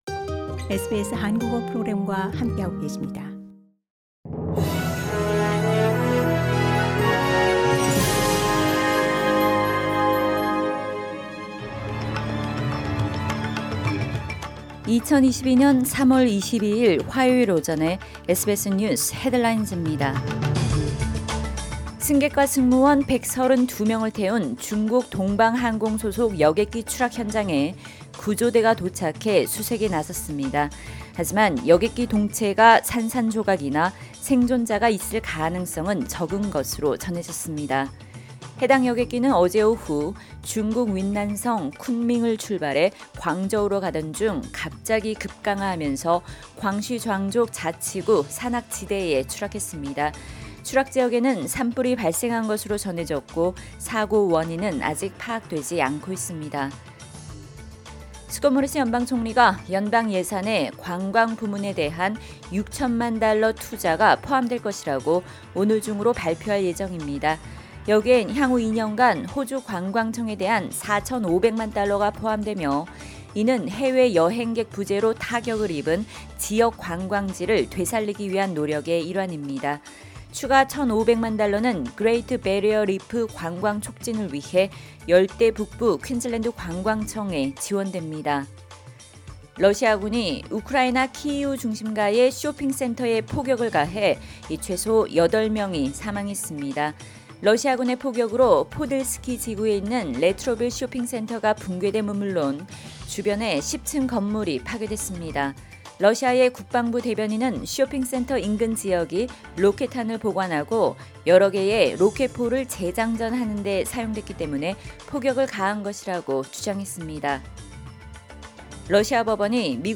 SBS News Headlines…2022년 3월 22일 화요일 오전 뉴스
2022년 3월 22일 화요일 오전 SBS 뉴스 헤드라인즈입니다.